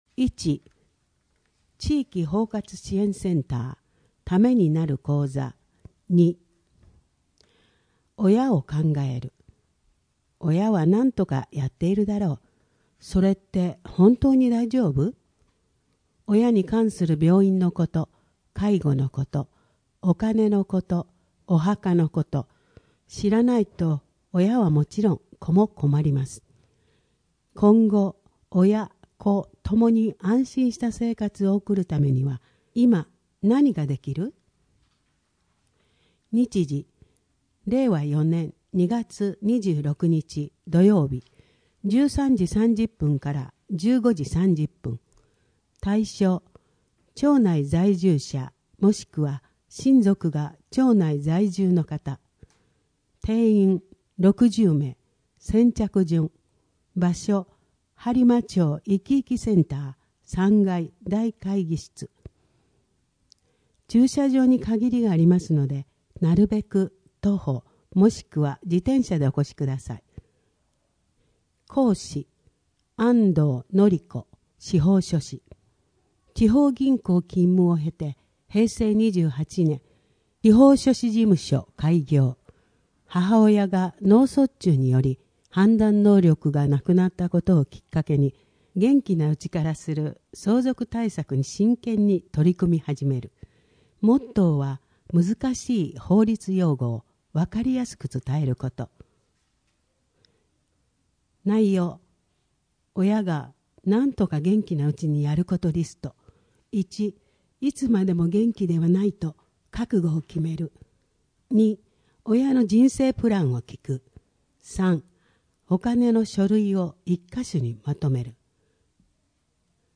声の広報